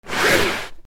布ずれ（引きずる）
/ J｜フォーリー(布ずれ・動作) / J-05 ｜布ずれ